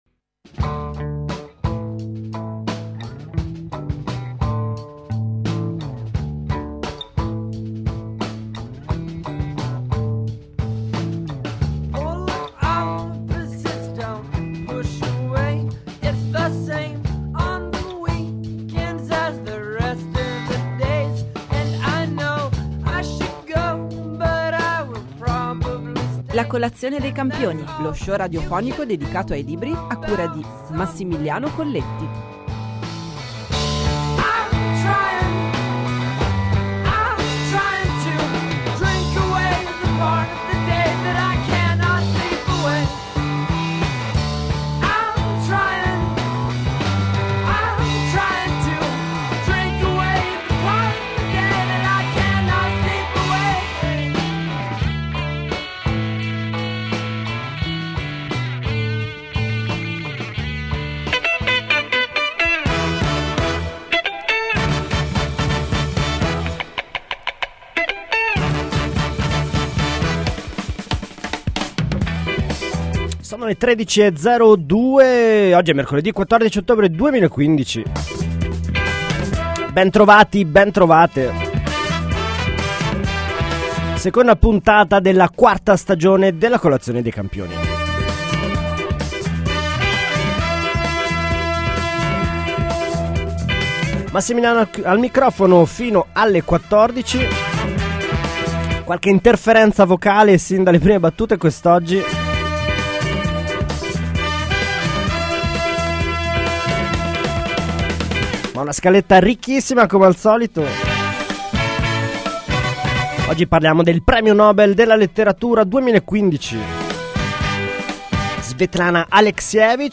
Con queste motivazioni l’otto ottobre scorso l’accademia svedese ha conferito il premio Nobel per la letteratura 2015 alla scrittrice sessantasettenne, metà ucraina e metà bielorussa, Svetlana Aleksievič. Per parlarne abbiamo raggiunto al telefono